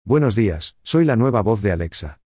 Alexa estrena una nueva voz: Así puedes activarla
Hasta ahora, Alexa tenía una voz femenina en castellano, pero, ahora, puedes escoger una nueva voz masculina con solo decir “Alexa, cambia tu voz”.
Esta nueva funcionalidad está disponible a partir de hoy y, para escuchar cómo suena esta nueva opción de voz, puedes hacerlo aquí:
Alexa-cambia-tu-voz_.mp3